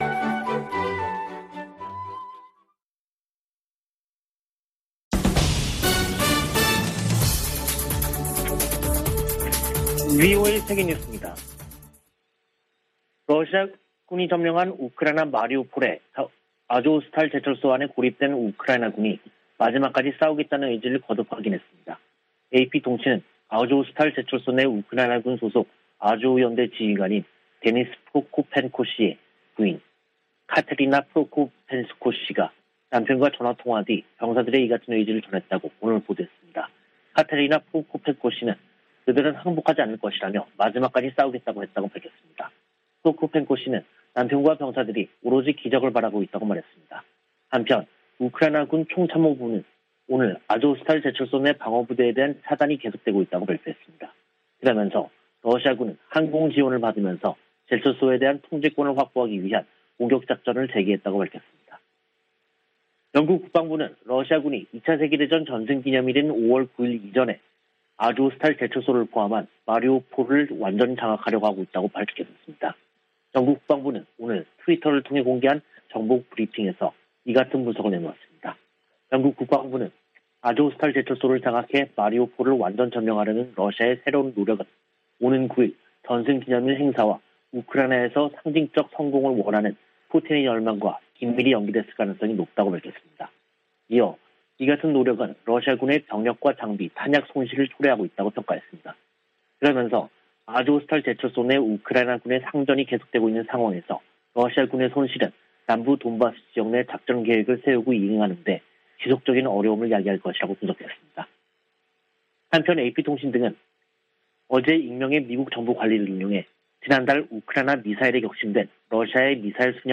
VOA 한국어 간판 뉴스 프로그램 '뉴스 투데이', 2022년 5월 6일 2부 방송입니다. 백악관은 조 바이든 대통령의 한일 순방에서 ‘확장억지’ 약속과, 북한 문제가 중점 논의 될 것이라고 밝혔습니다. 미 국무부는 북한의 거듭되는 미사일 발사에 대응이 따를 것이라는 분명한 신호를 보내야 한다고 강조했습니다. 미 상원이 필립 골드버그 주한 미국대사 인준안을 가결했습니다.